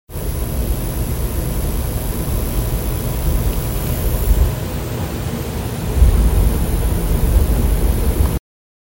Electrical Buzzing noise coming from Studio Display
Anyone else have an electrical buzzing noise coming from the Studio Display?
I have attached a recording of the noise.